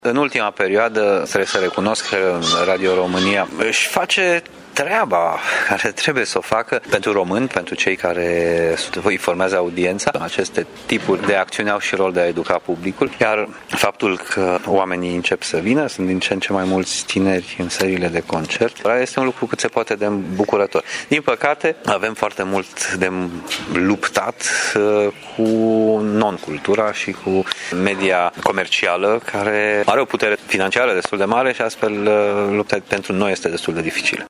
Chiar dacă publicul din sălile de concerte este tot mai numeros și mai tânăr, artiștii au de dus o luptă grea cu non-cultura și cu media comercială, spune pianistul Horia Mihail: